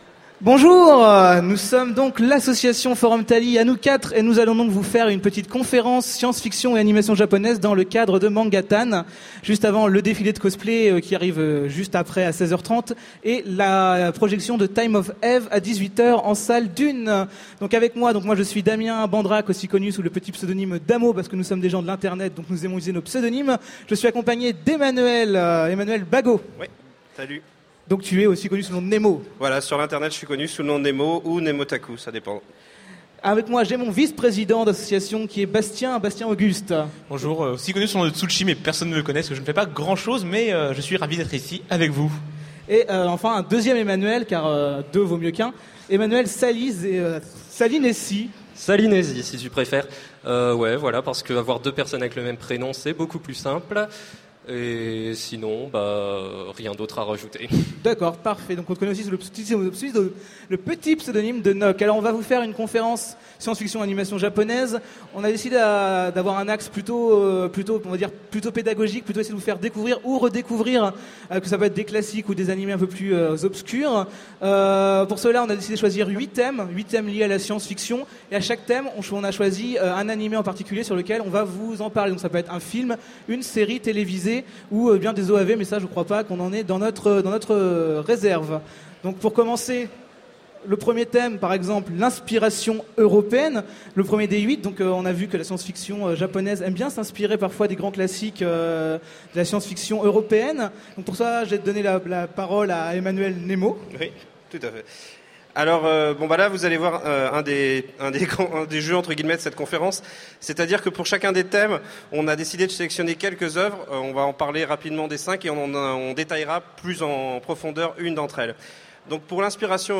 Utopiales 2014
Conférence